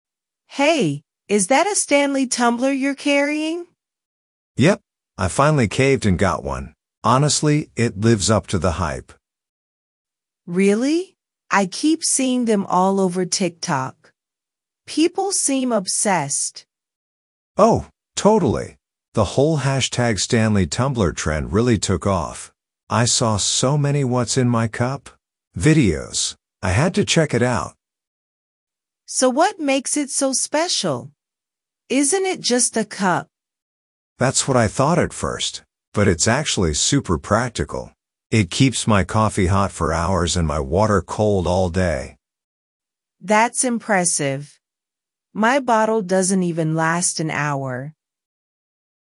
Stanley_Tumbler_Craze_Dialogue.mp3